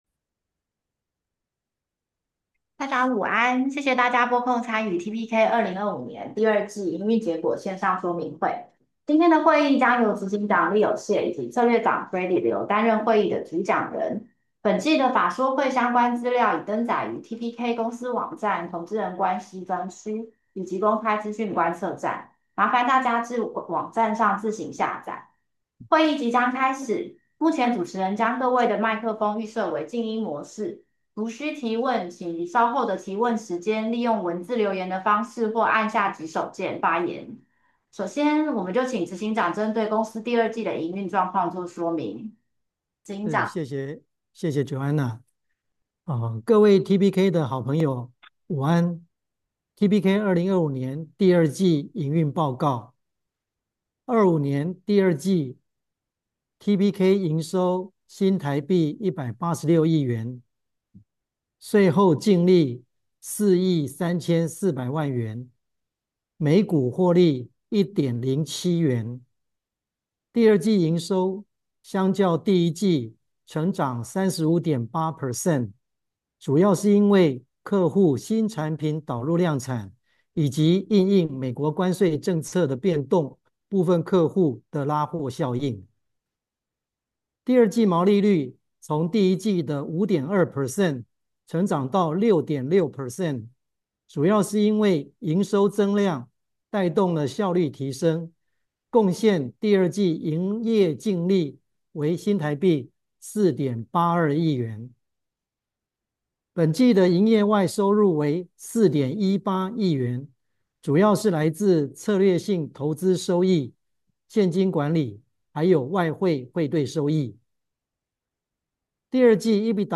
TPK Q2 2025 Investor Conference
Analyst Meeting